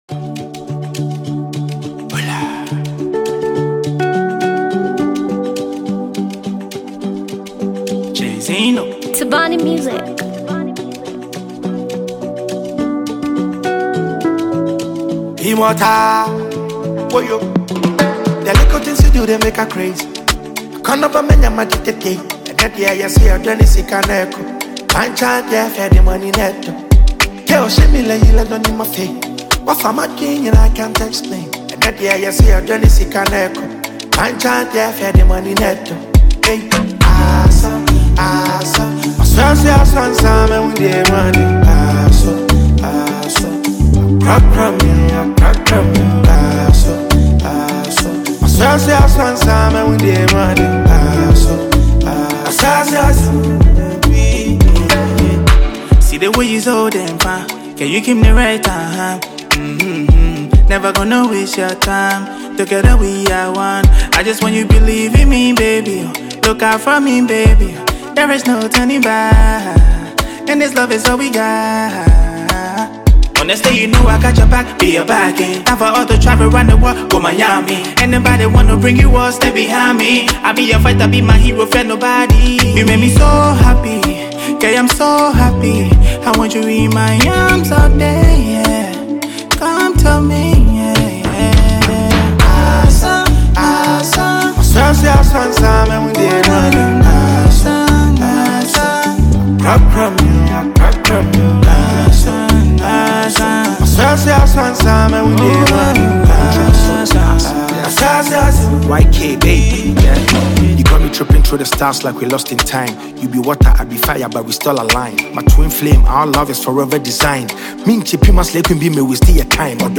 wrapped in a catchy, energetic beat